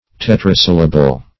Search Result for " tetrasyllable" : The Collaborative International Dictionary of English v.0.48: Tetrasyllable \Tet"ra*syl`la*ble\, n. [Tetra- + syllable: cf. Gr.